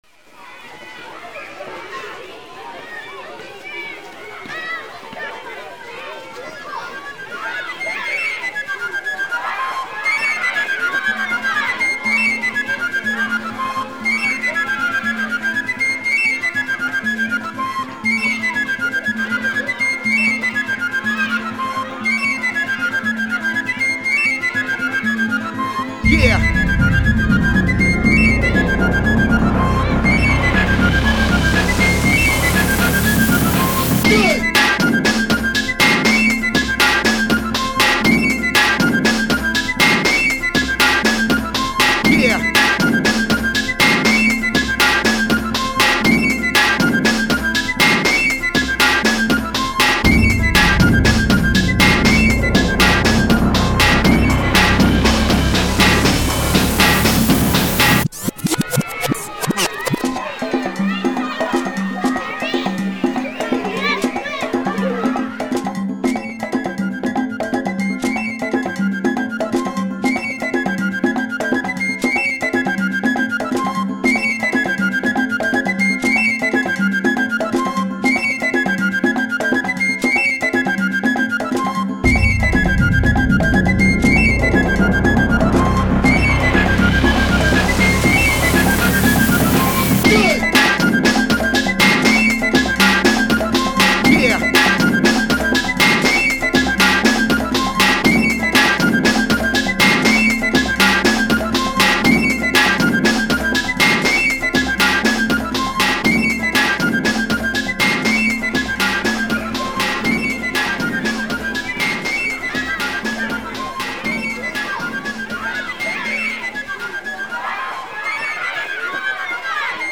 mad-flute-jamborie.mp3